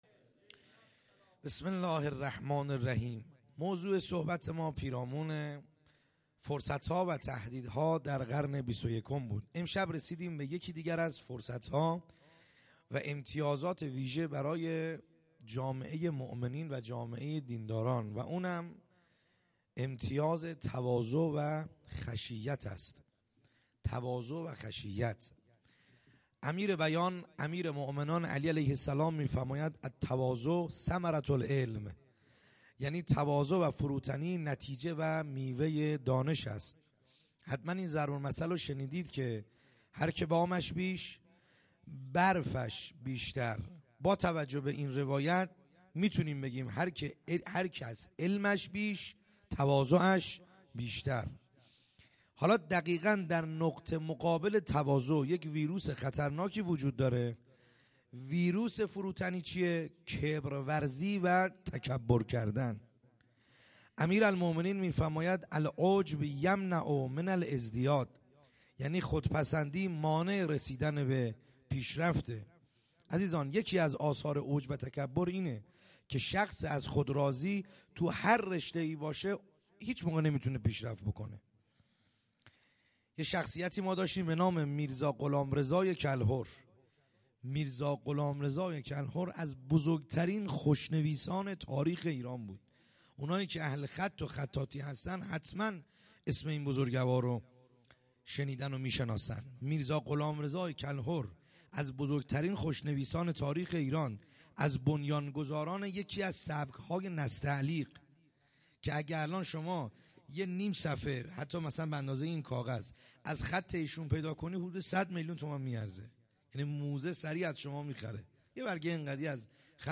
خیمه گاه - بیرق معظم محبین حضرت صاحب الزمان(عج) - سخنرانی | شب هجدهم